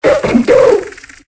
Cri de Miamiasme dans Pokémon Épée et Bouclier.